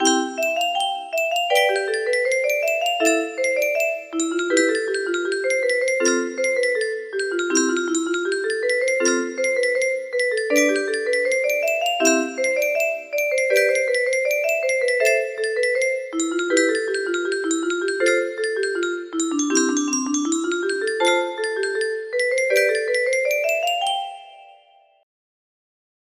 Clone of Canon C music box melody